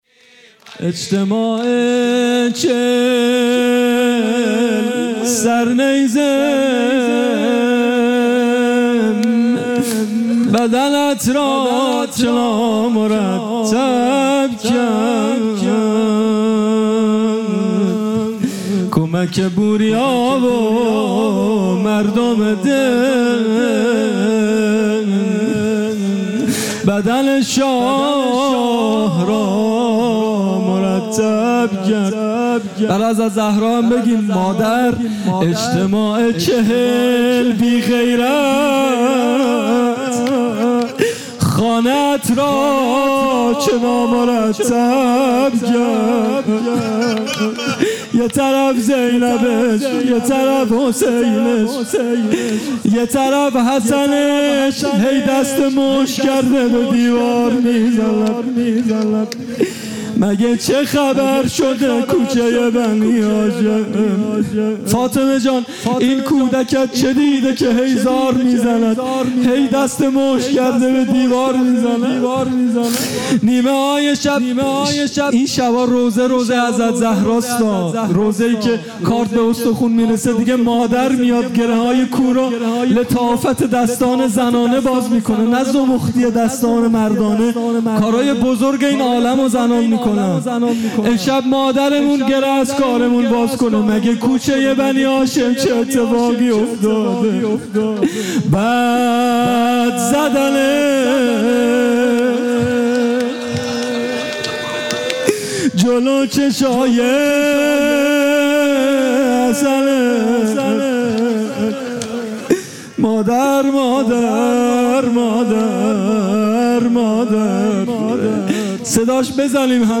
شب پنجم مراسم مناجات‌خوانی | در جوار مزار نورانی شهدای هویزه ۱۴۰۲